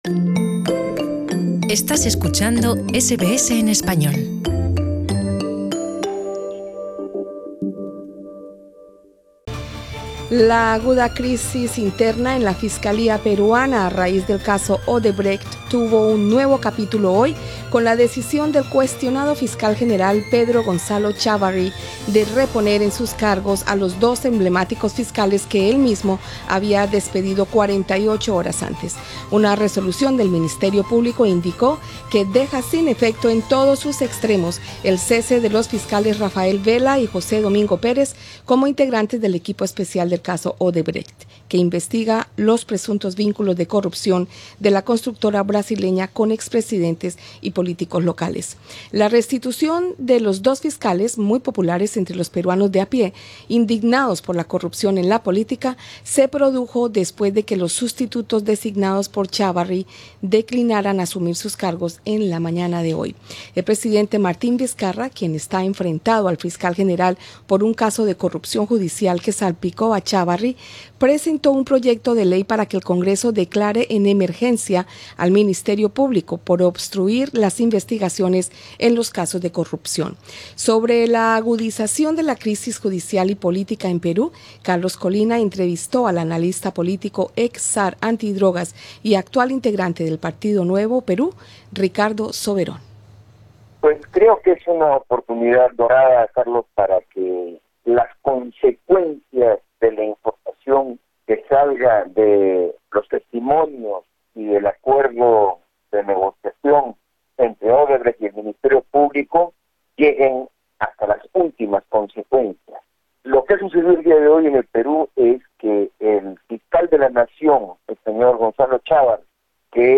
Sobre la agudización de la crisis judicial y política en Perú, entrevista con el analista político